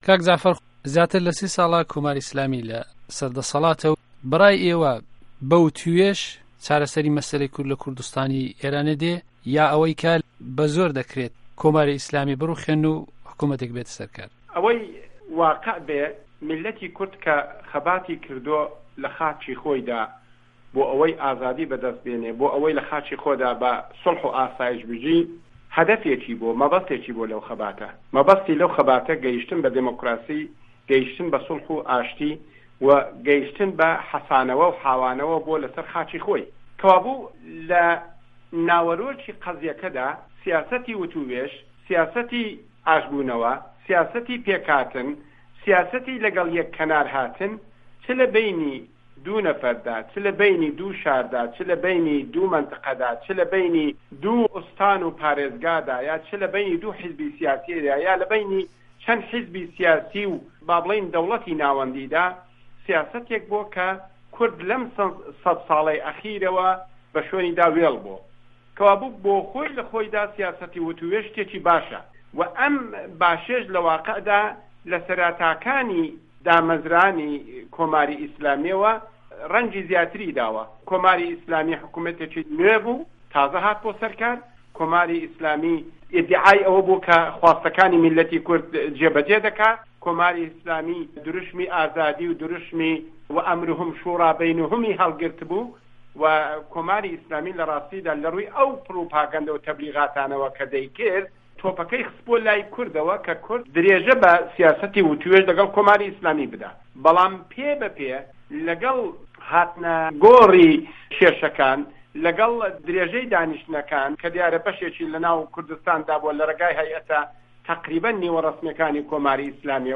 وتووێژی